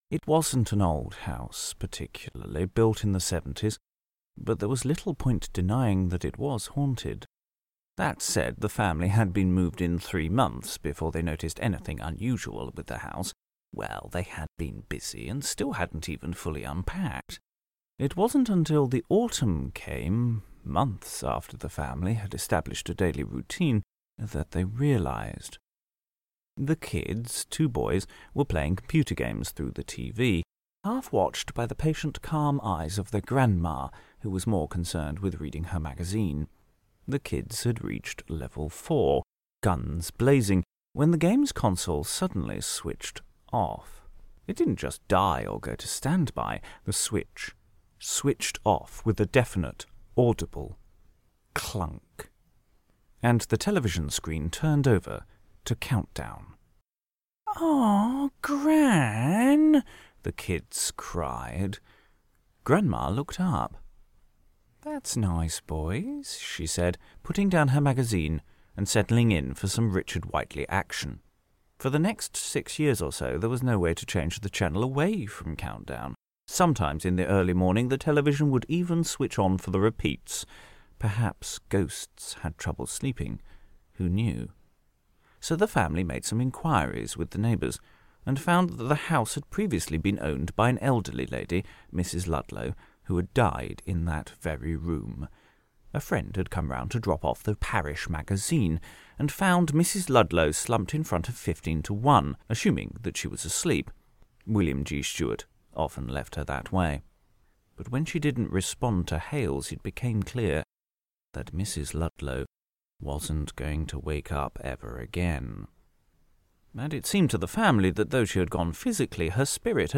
A short story, first performed as part of Ghost Storyteller in 2011, telling of the slow fading of a poltergeist and its daily routine.